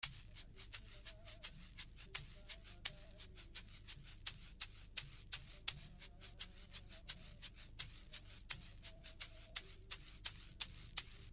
85 BPM Shaker (5 variations)
5 loops of egg shaker playing in 85 bpm.